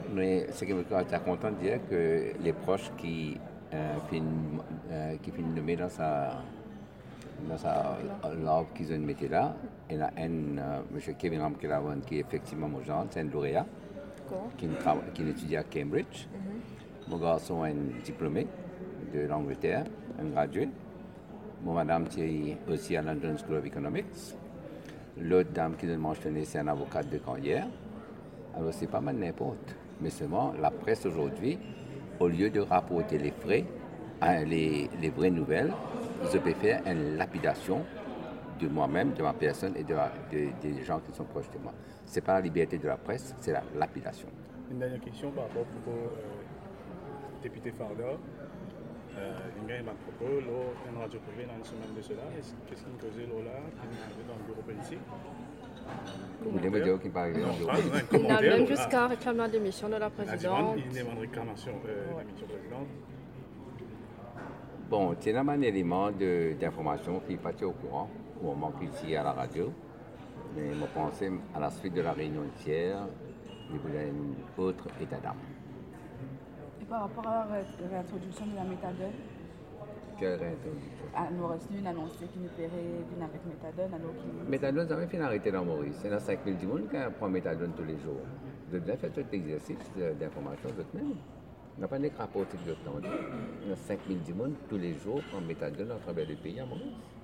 Le ministre du Tourisme, Anil Gayan intervenait lors de l'ouverture d’un atelier de travail sur le développement touristique durable, mercredi 3 mai.